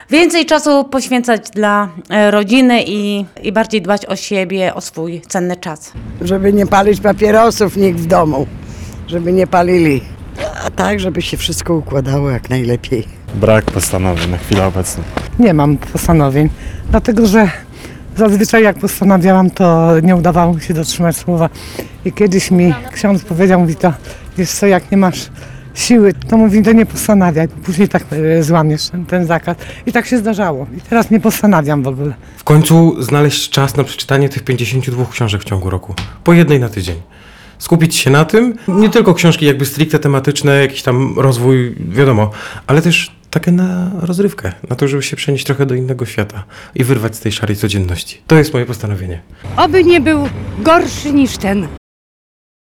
Zapytaliśmy płocczan, jakie mają postanowienia na 2026 rok.